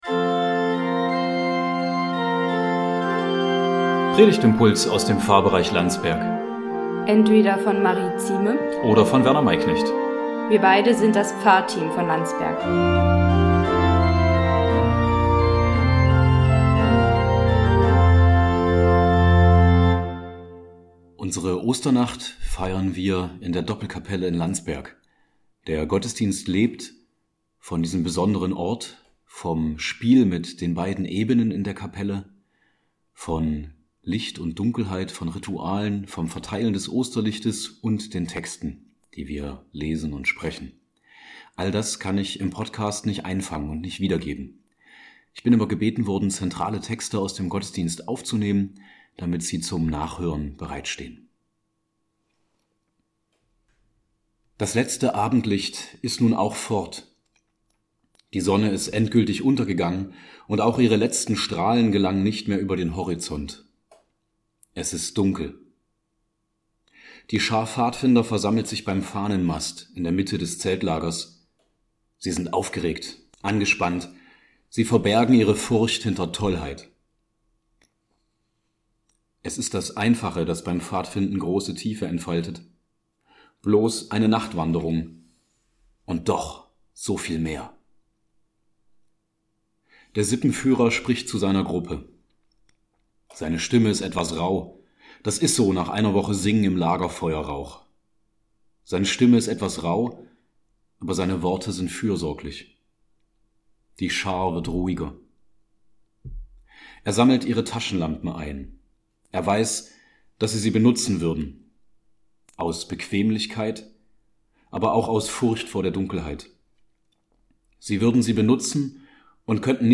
Predigtimpulse aus dem Pfarrbereich Landsberg